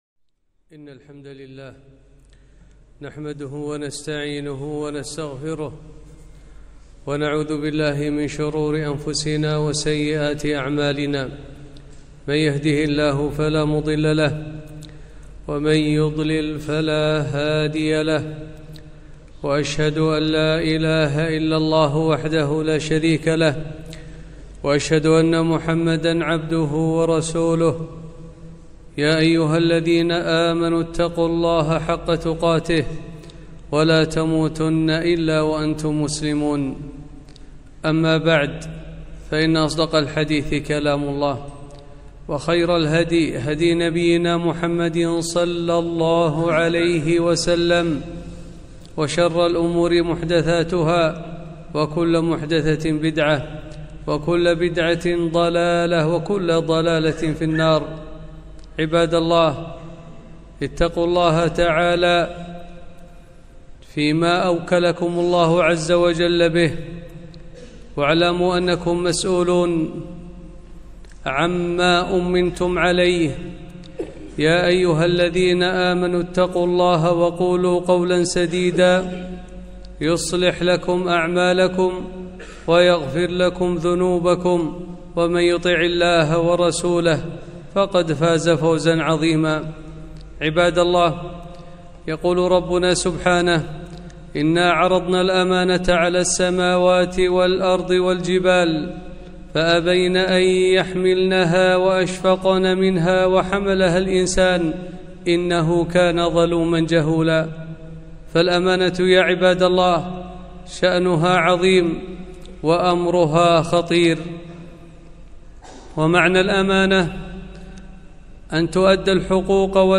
خطبة - تربية الأولاد أمانة ومسؤولية